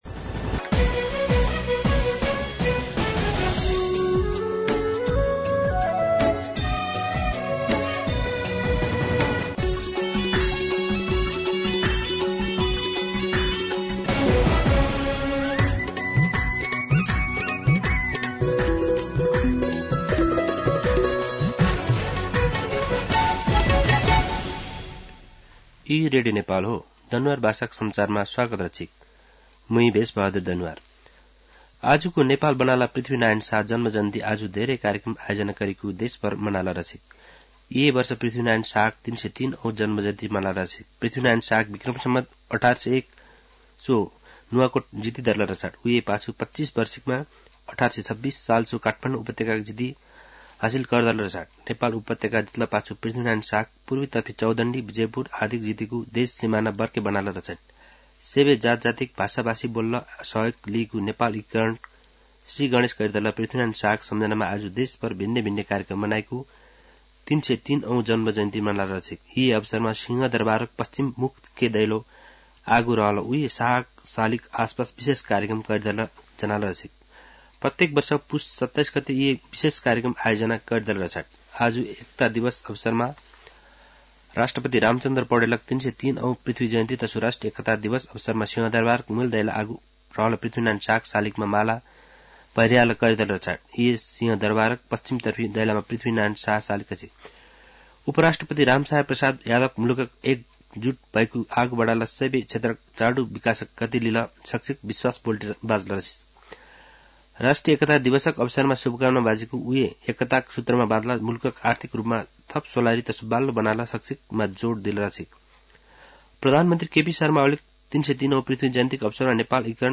दनुवार भाषामा समाचार : २८ पुष , २०८१
Danuwar-News-8-27.mp3